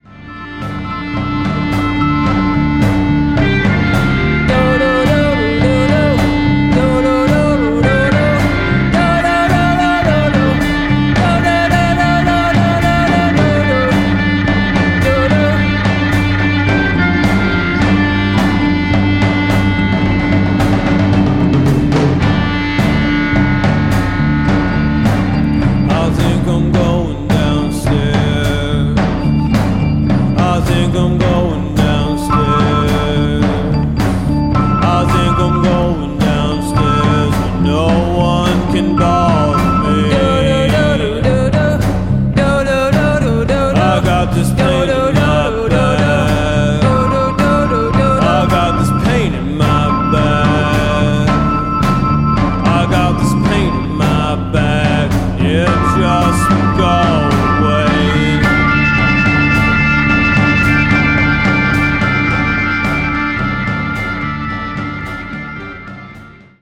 long-form paeans to tar-blackened bummer psych.
Recorded at Bank Row Recording, Greenfield, MA